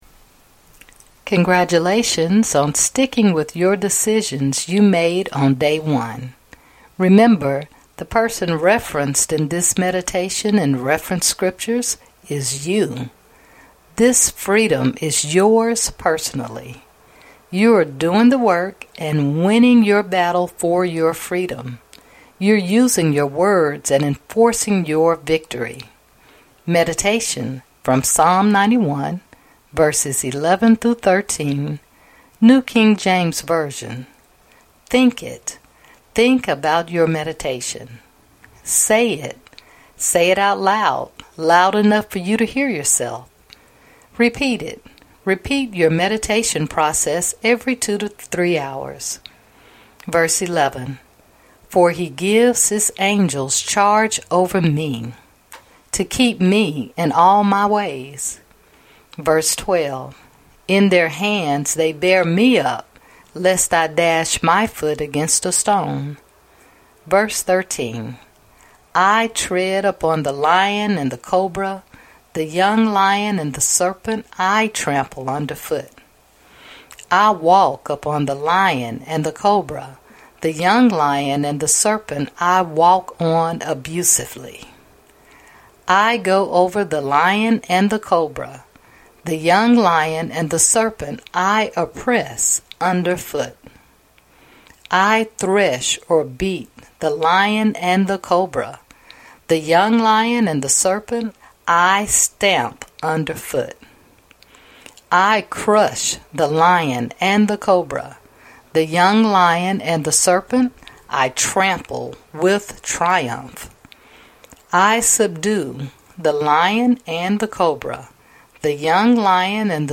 Meditation... From Psalm 91, Verses 11-13 NKJV